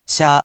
In romaji, 「しゃ」 is transliterated as「sha」which sounds sort of like the word 「shah」.
Rather than the incorrect ‘✖Shee-yah’, it is pronounced as simply ‘〇shah’.